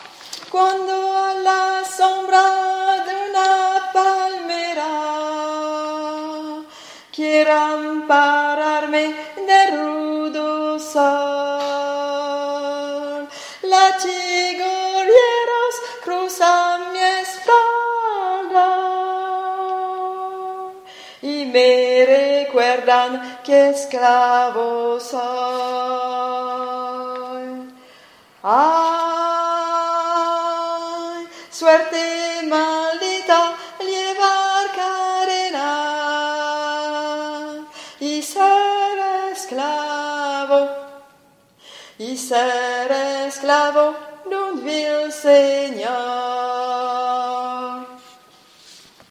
les sons pour apprendre, par vos cheffes préférées